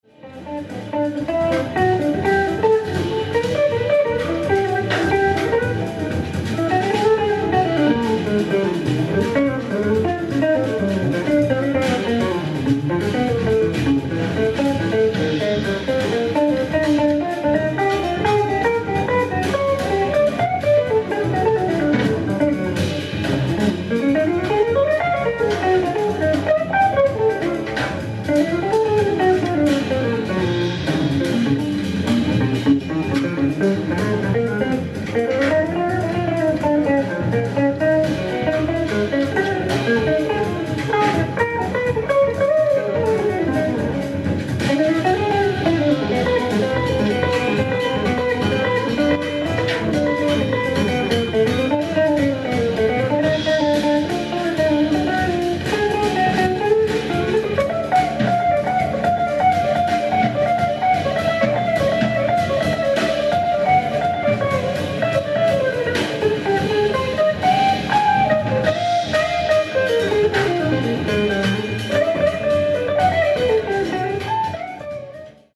ライブ・アット・ブルーノート、東京 12/17/2003
※試聴用に実際より音質を落としています。